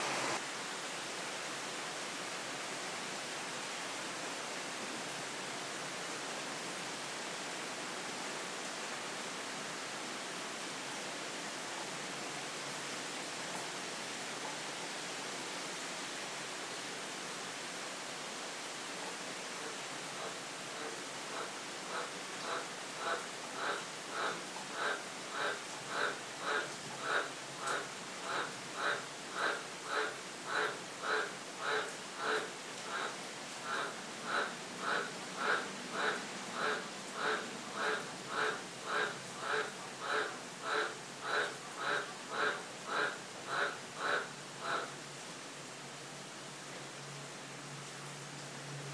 Rain & frogs :)